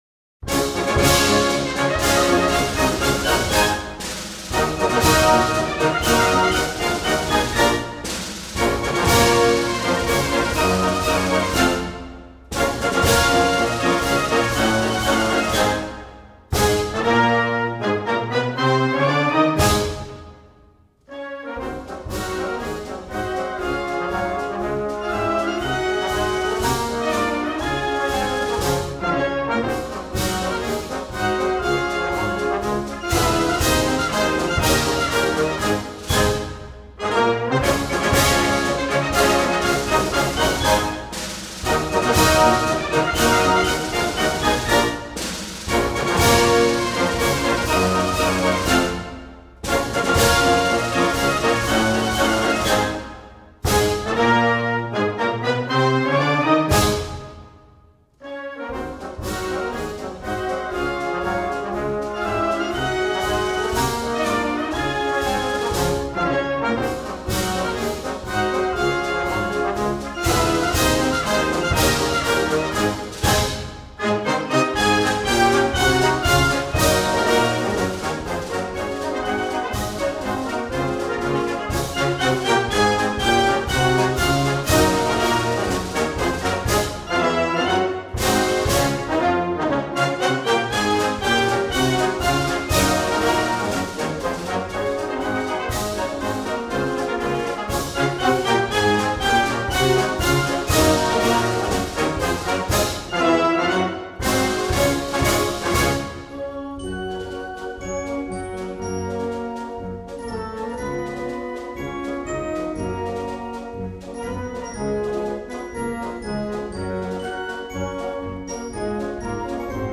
Piece Style: Early 20th century